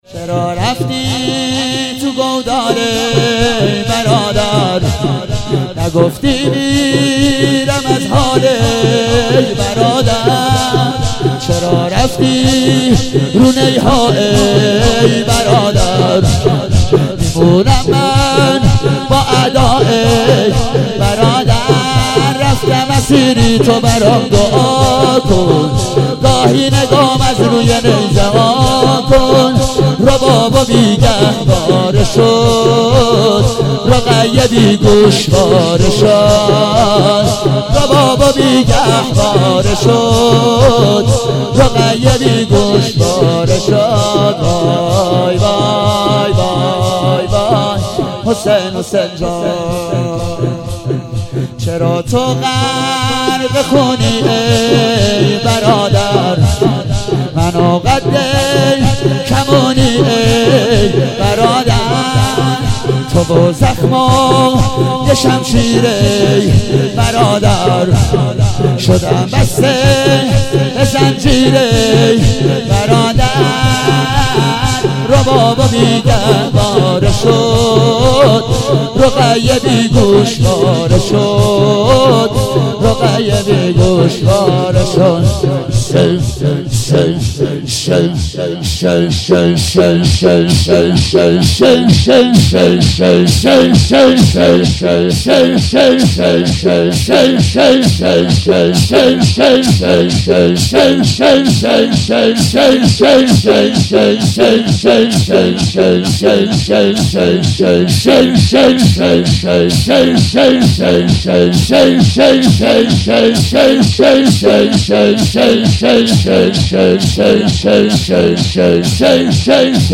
هفتگی 26 مهر 97 - شور - چرا رفتی تو گودال